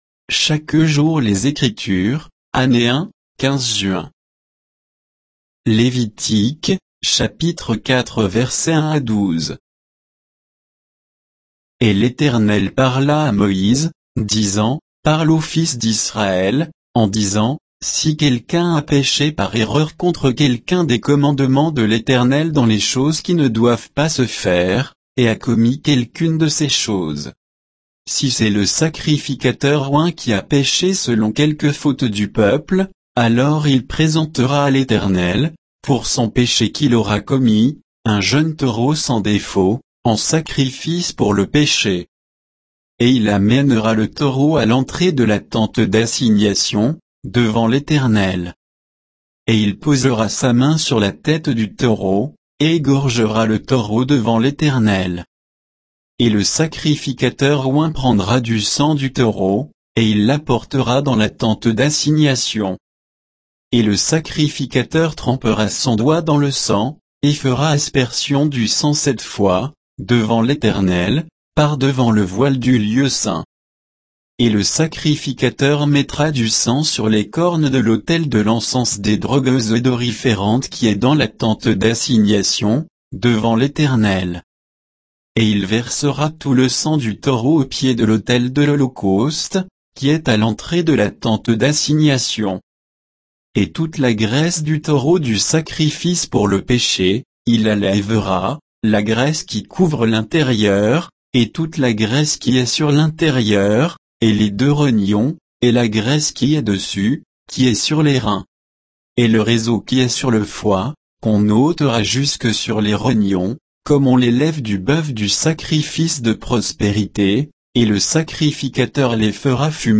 Méditation quoditienne de Chaque jour les Écritures sur Lévitique 4